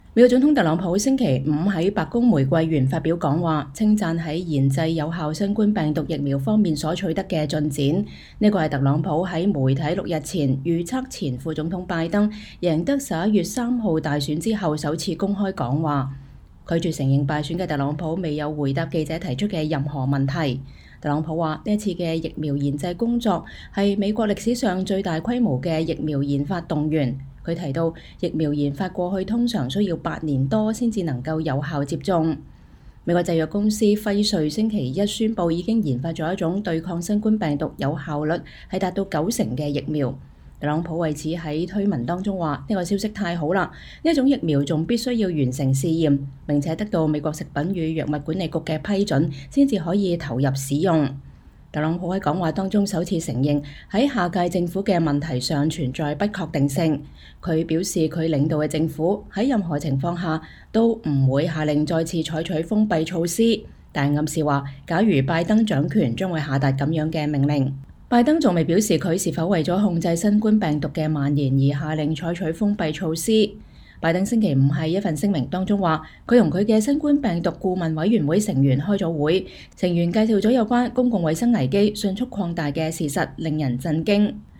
特朗普總統白宮玫瑰園講話 稱讚疫苗研製取得的進展
美國總統特朗普星期五(11月13日)在白宮玫瑰園發表講話，稱讚在研製有效新冠病毒疫苗方面所取得的進展。這是特朗普在媒體六天前預測前副總統拜登贏得11月3日大選後首次公開講話。